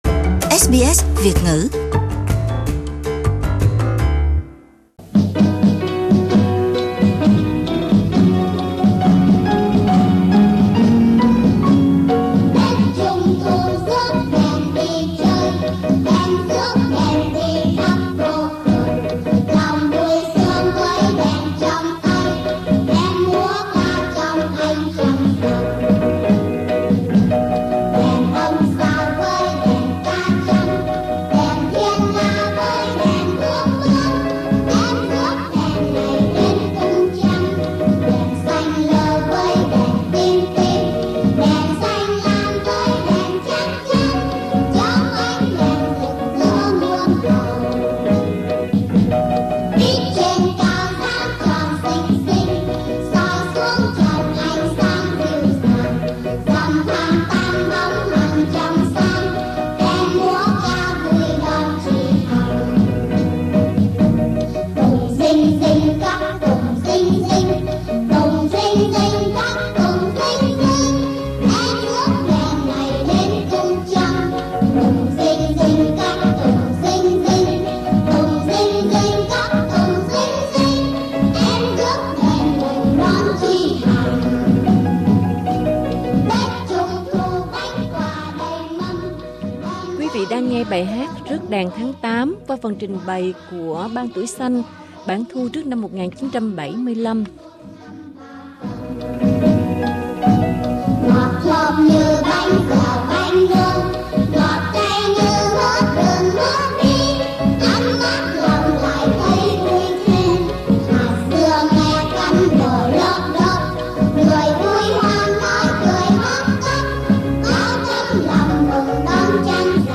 Tháng trăng rằm, nghe lại các bài hát thiếu nhi đi cùng năm tháng, gợi nhớ về thời thơ ấu tắm mình với những câu chuyện cổ tích, với dế mèn và ánh trăng, với Chú Cuội, chị Hằng và Thỏ Ngọc, và những chiếc lồng đèn huyền thoại vẫn còn lung linh trong ký ức của mỗi người.